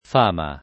fama [ f # ma ] s. f.